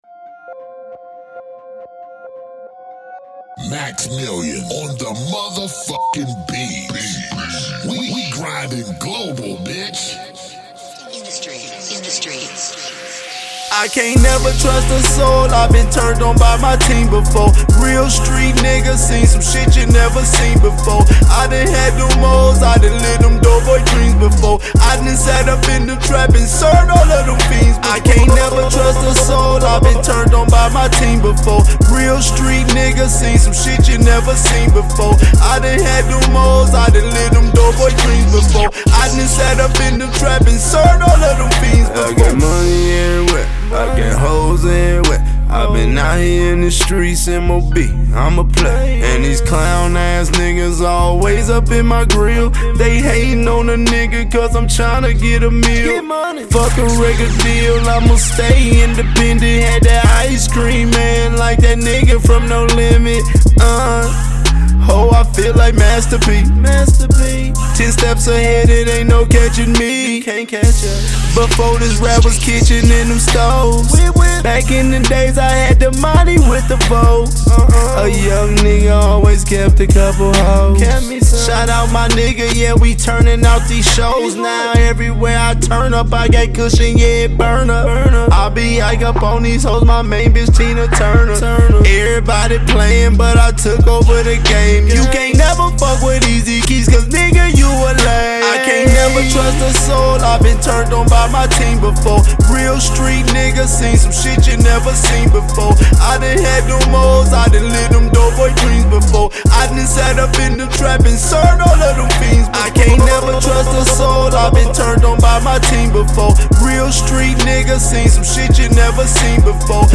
Hiphop
Description : Raw Gritty Midwest Ohio music!!!